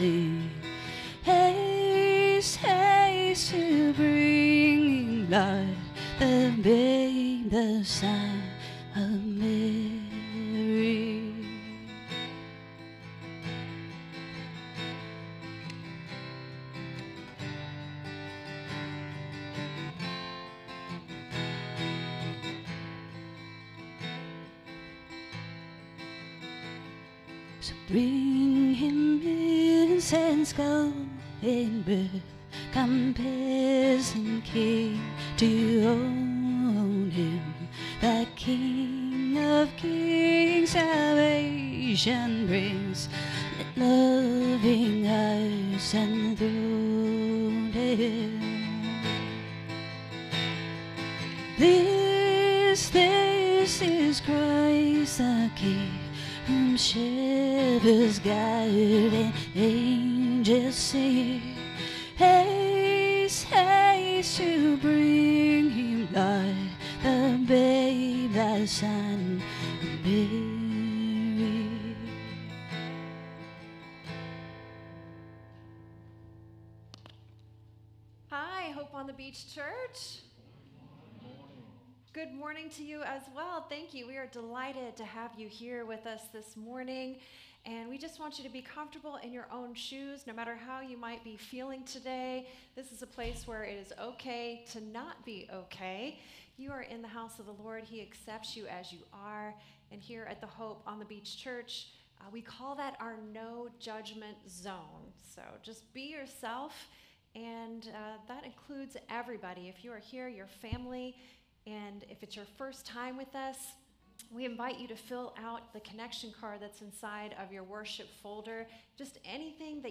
SERMON DESCRIPTION God is love, revealed in His promise and fulfilled in the birth of Jesus Christ.